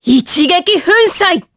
Priscilla-Vox_Skill1_jp.wav